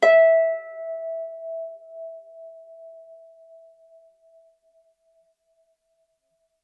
KSHarp_E5_mf.wav